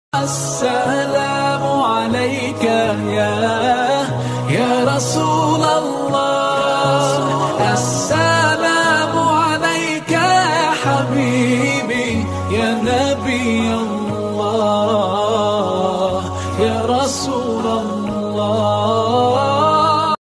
Best Islamic ringtone for mobile.